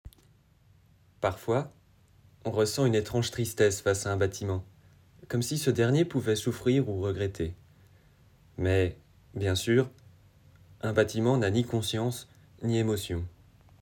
Bandes-son
Voix off
20 - 35 ans - Baryton-basse